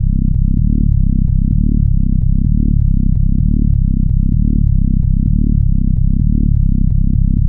Index of /90_sSampleCDs/Club_Techno/Bass Loops
BASS_128_C.wav